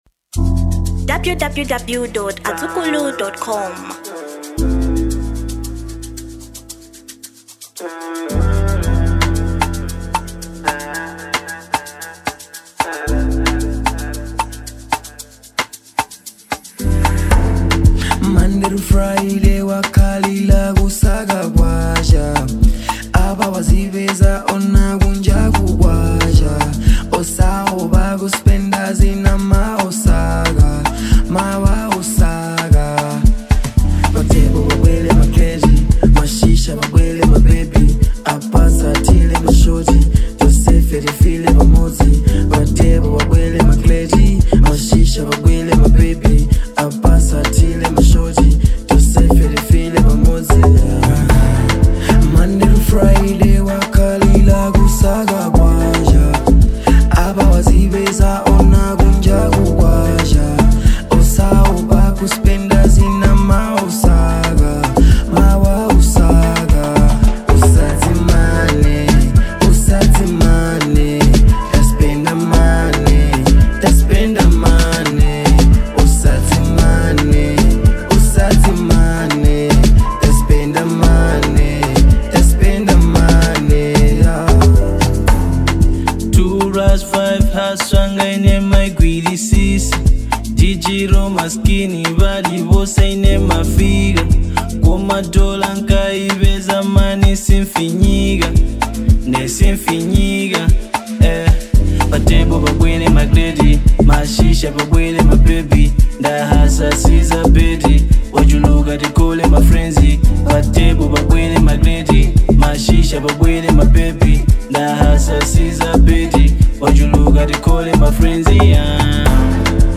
Genre Amapiano